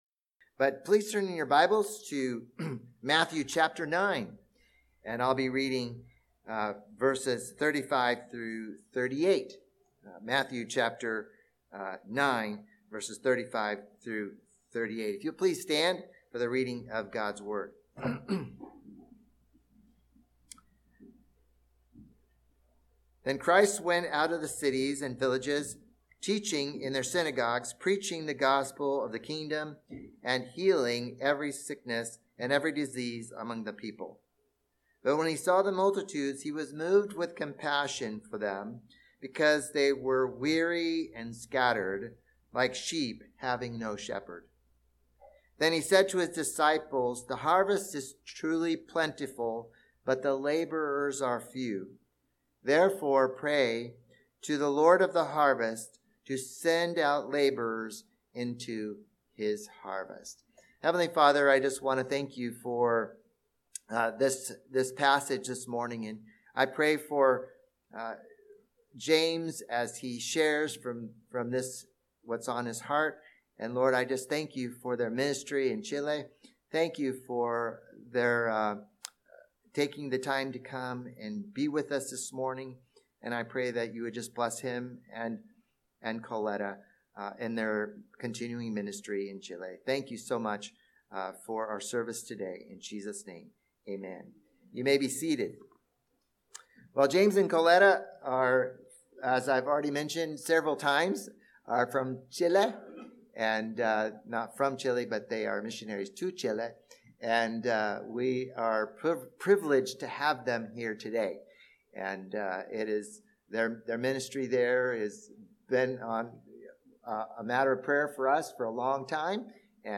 Special Sermon